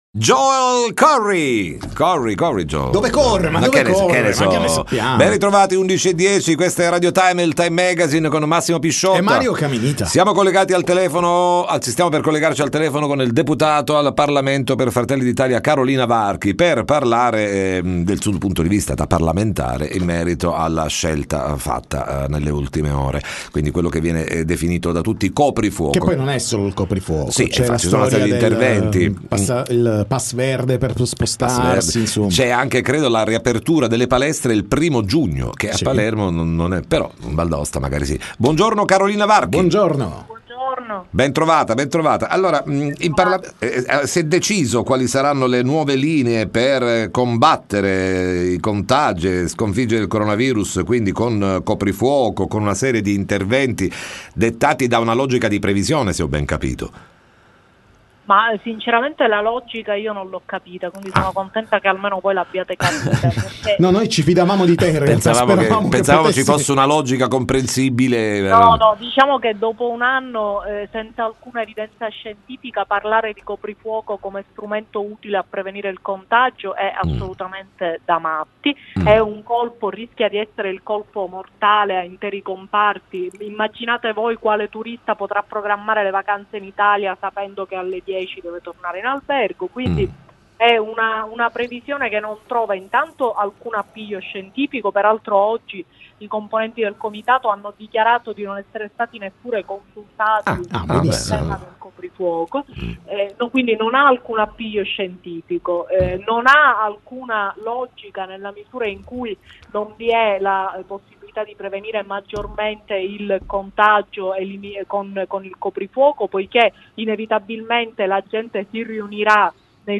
TM intervista Carolina Varchi